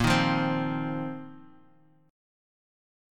Bbsus2sus4 Chord
Listen to Bbsus2sus4 strummed